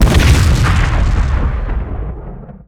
plane_explosion3.wav